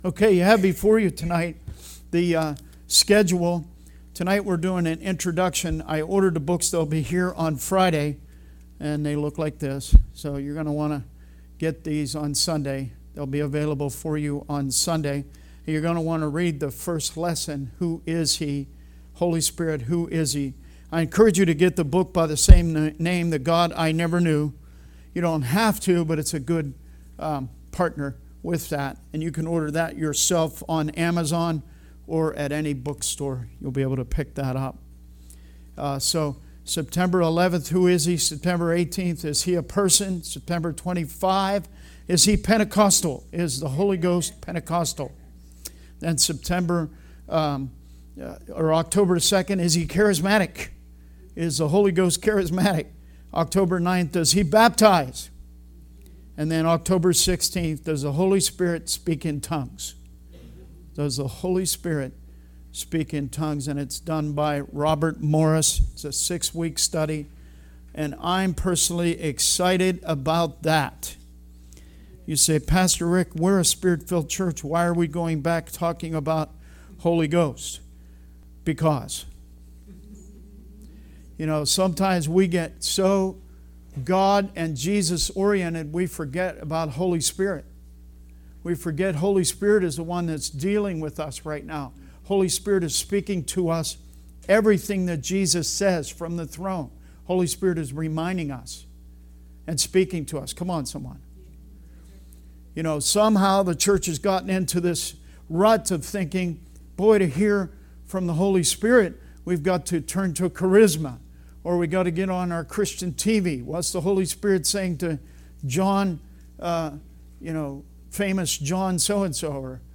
Sermon messages available online.
Service Type: Wednesday Teaching